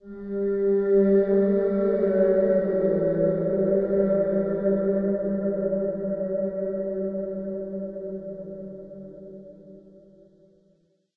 ballCollected.ogg